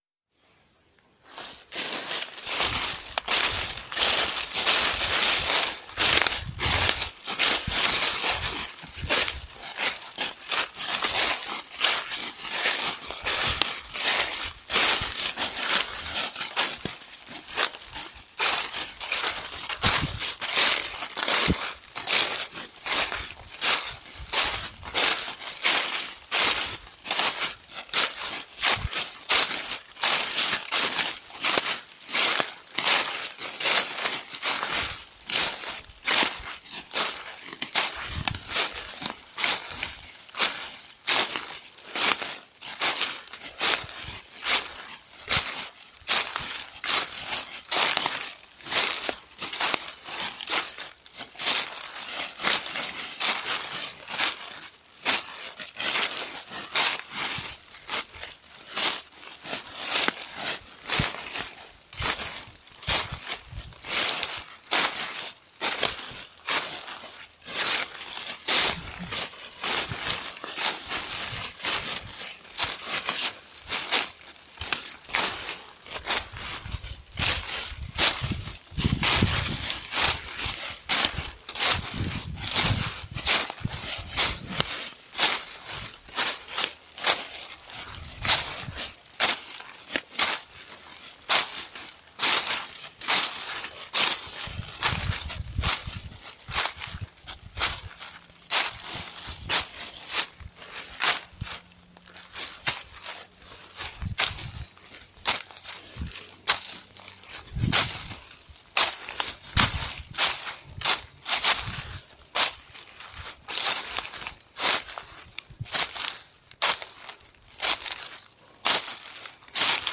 Pisadas
A 30 minutos del centro de Tuxtepec se localiza La mina. El paisaje en las cercanías del poblado se mezcla entre los sembradíos de caña, los platanares y los árboles de hule.